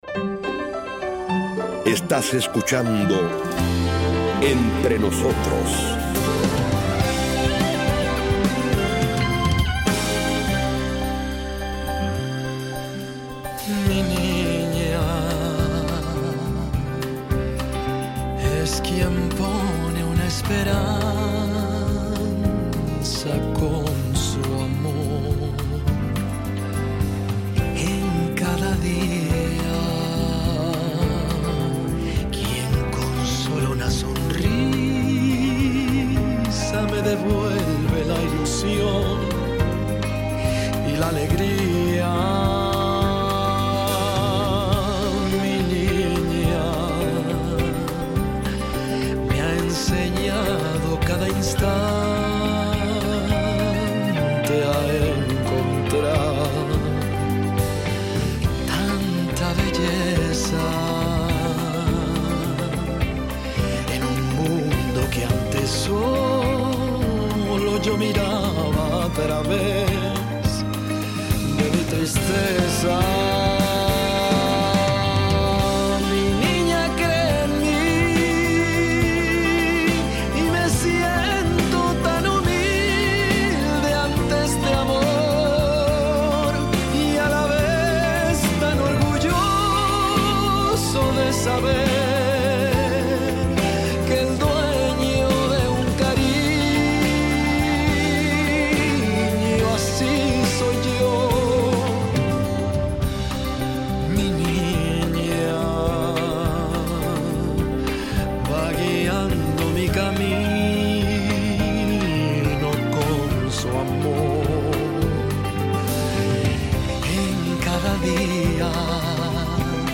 La escritora Delphine de Vigan habla sobre el miedo a su padre, el suicidio de su madre, el acoso a las mujeres, la anorexia, el temor a herir a los demás y la soledad de los niños a quienes sus mayores no prestan atención.